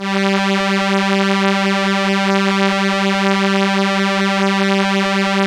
Index of /90_sSampleCDs/Keyboards of The 60's and 70's - CD1/STR_Elka Strings/STR_Elka Violins
STR_ElkaVlsG_4.wav